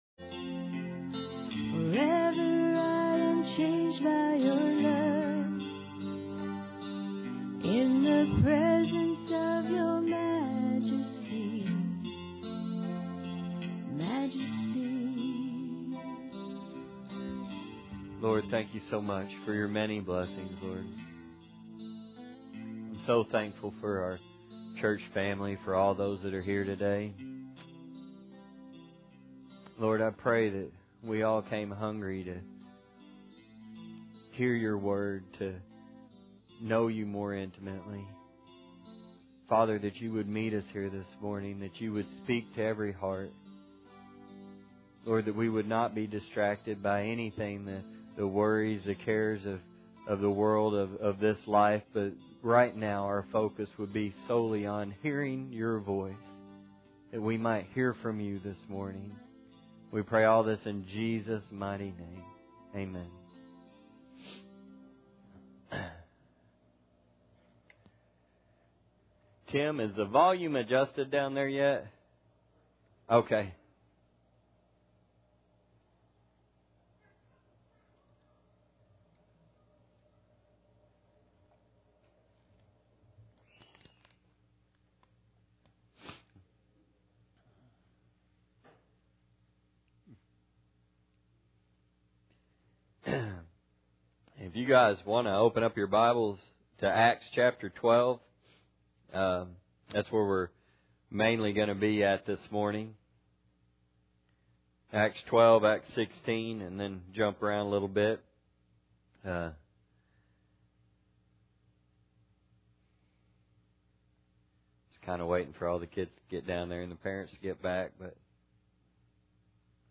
Service Type: Sunday Morning Bible Text